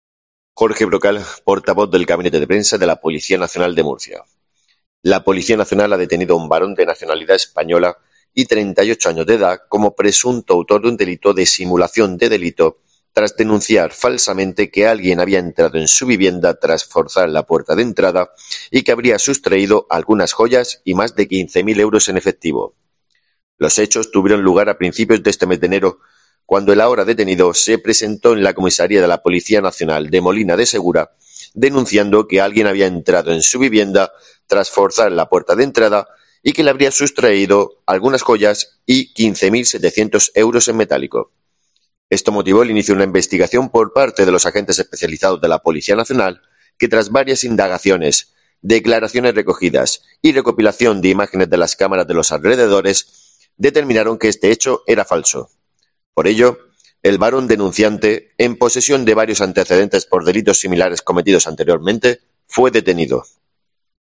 SUCESOS